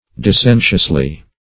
-- Dis*sen"tious*ly , adv.